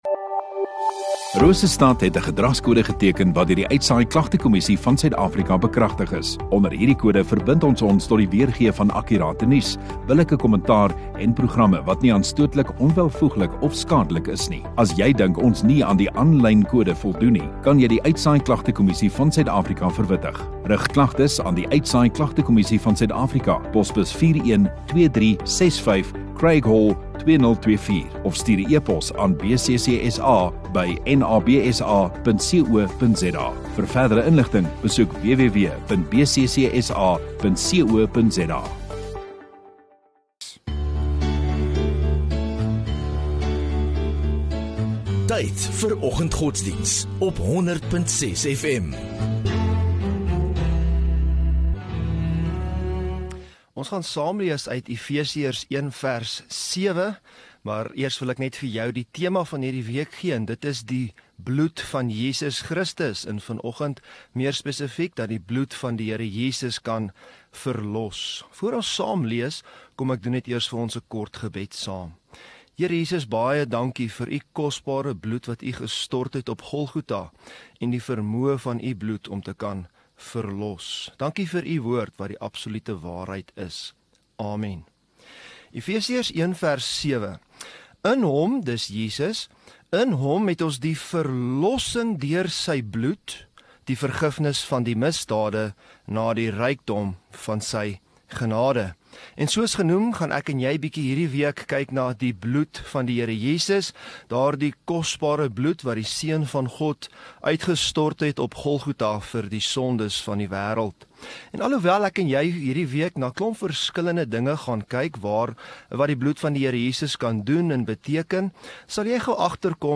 29 Sep Maandag Oggenddiens